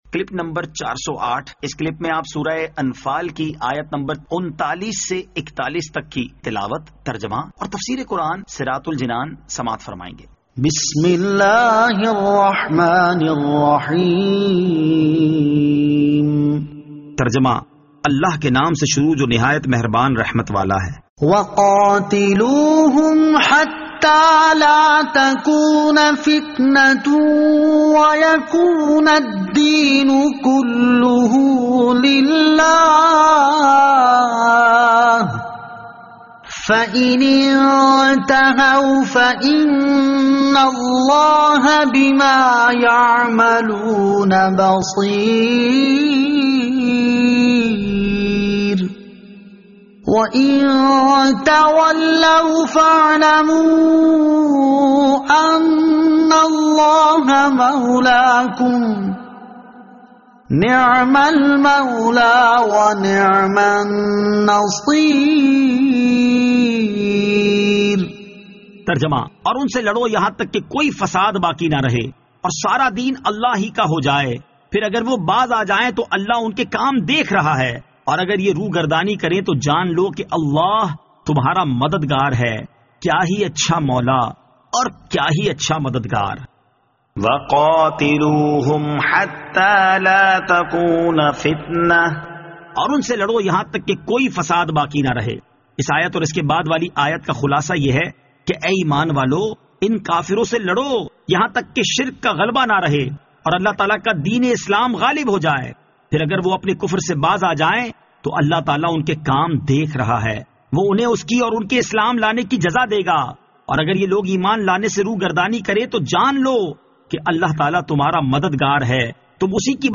Surah Al-Anfal Ayat 39 To 41 Tilawat , Tarjama , Tafseer